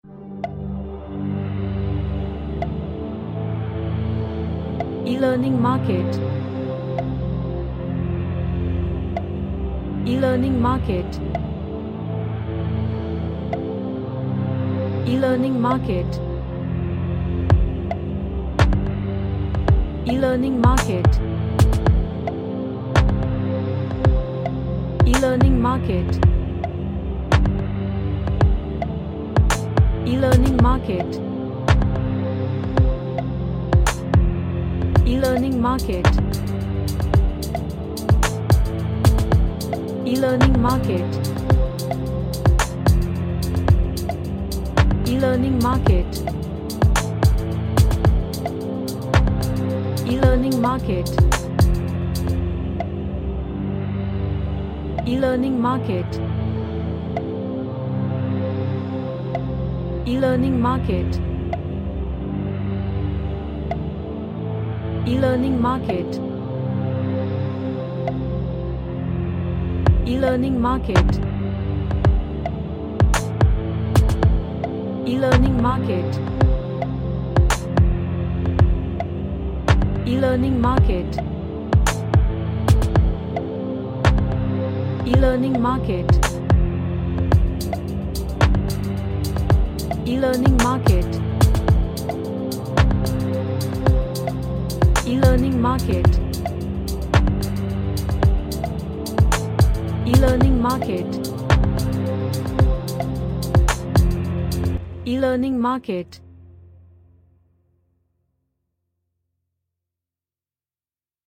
An Ambient relaxing track.
Relaxation / Meditation